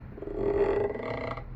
预发射音效.mp3